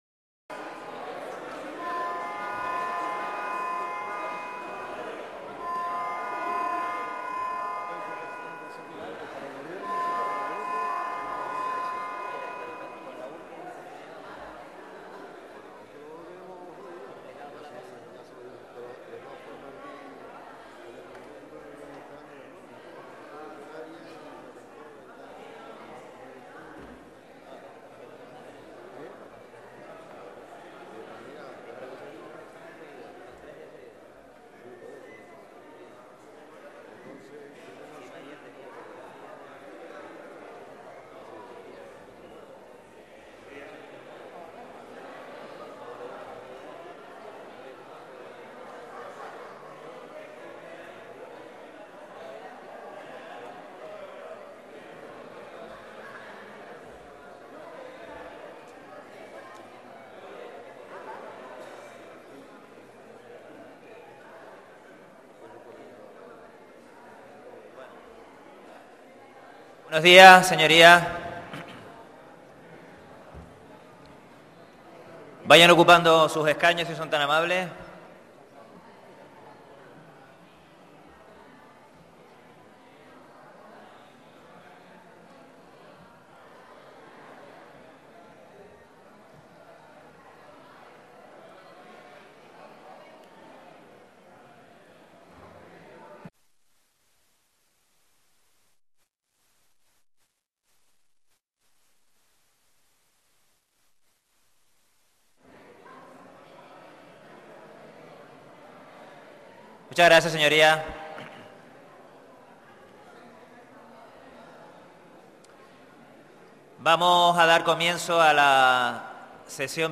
Grabación Salón de Plenos